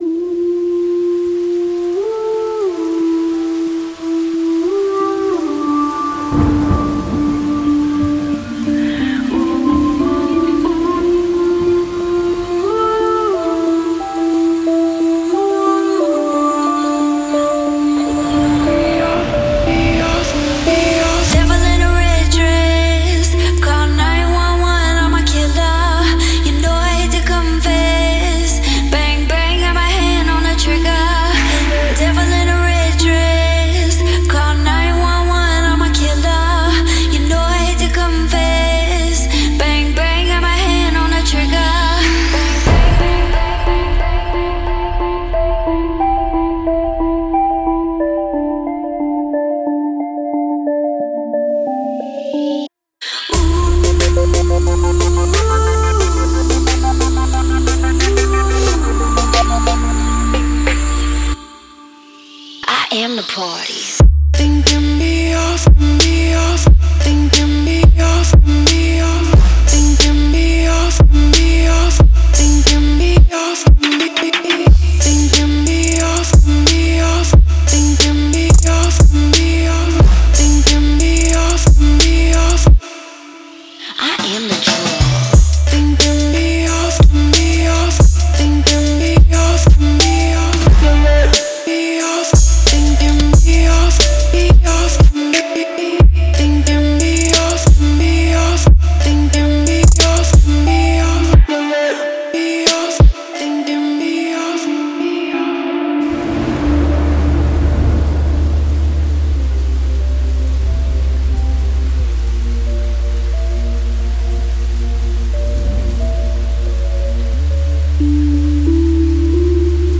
энергичная электронная композиция